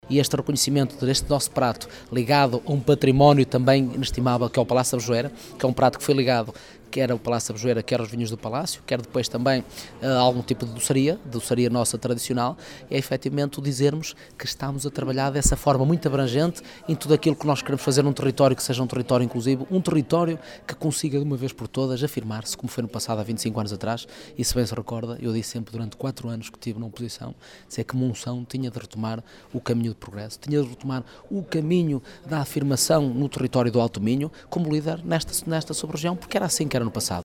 A Rádio Vale do Minho já falou com o presidente da Câmara que se mostrou bastante orgulhoso com este resultado.